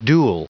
Prononciation du mot dual en anglais (fichier audio)
Prononciation du mot : dual